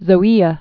(zō-ēə)